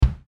(drums)